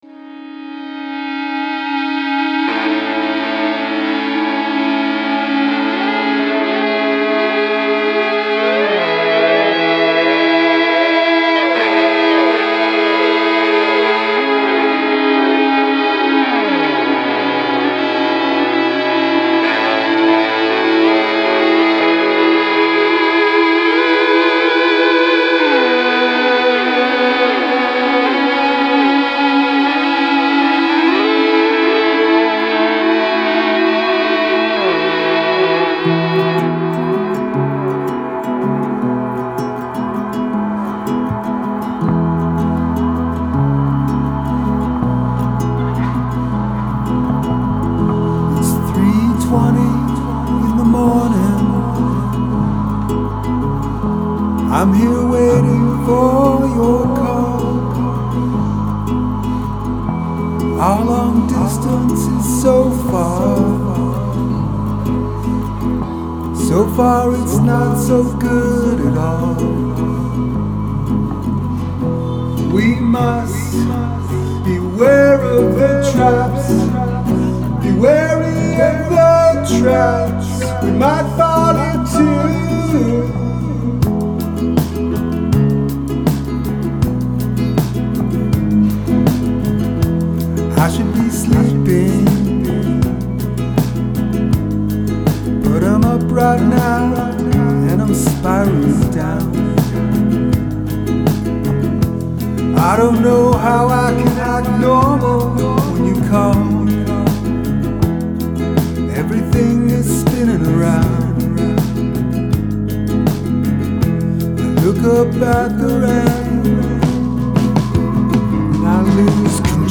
Ambience
tuneless singing. Not boy rock. Worse. 😉